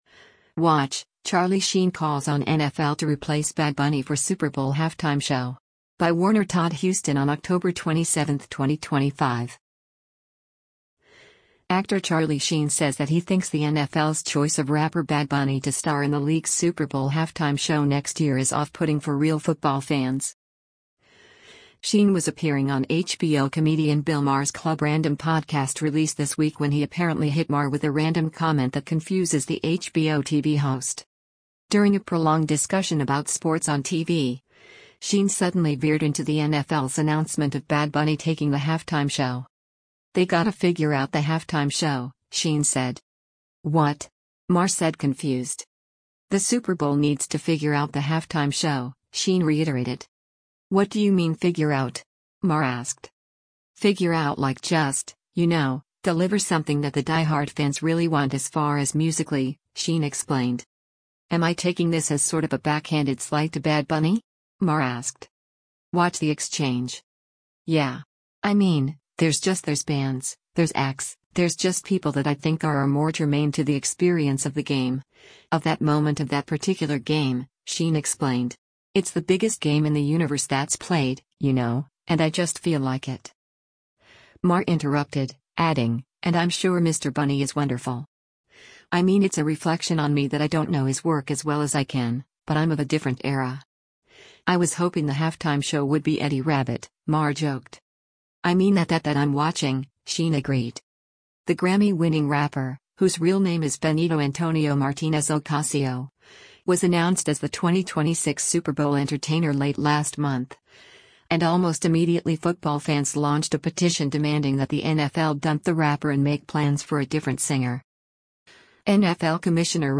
Sheen was appearing on HBO comedian Bill Maher’s Club Random podcast released this week when he apparently hit Maher with a random comment that confuses the HBO TV host.